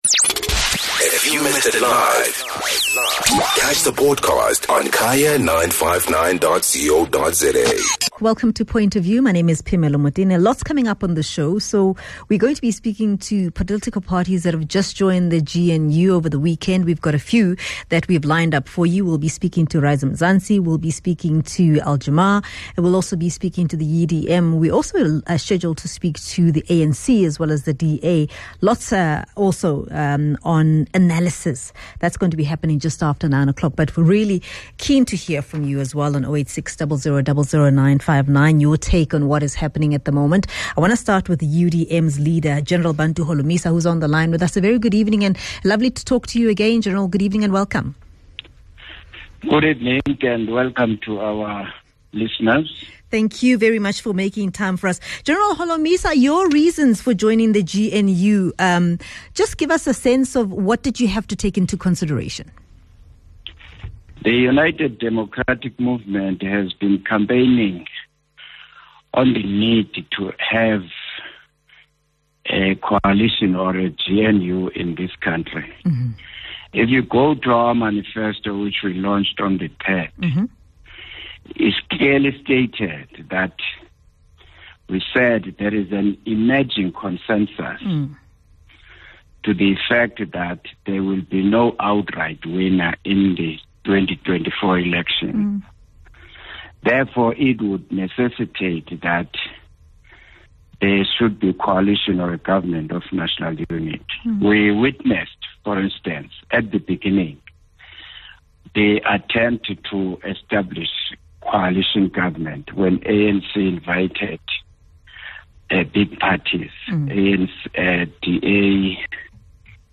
Guest: Bantu Holomisa - UDM Leader